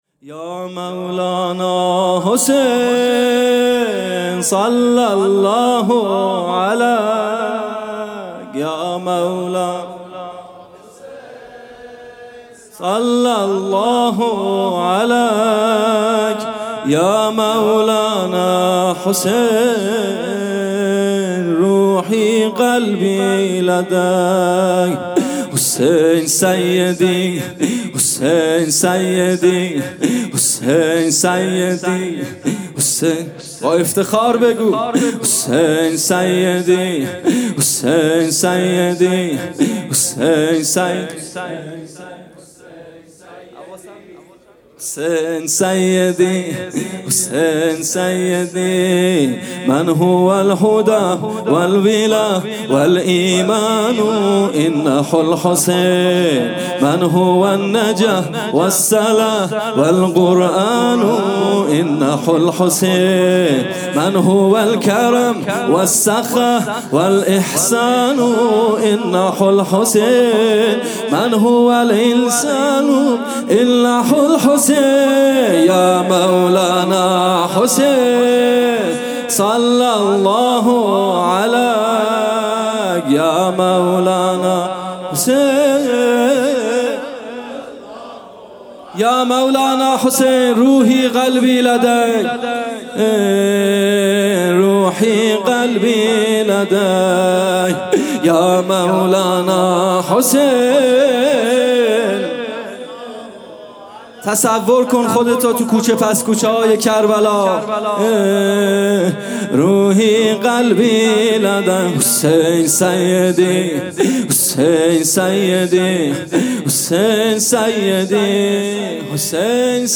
جلسه هفتگی
music-icon سرود: یا مولانا حسین